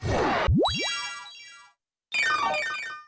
Present - Heal.mp3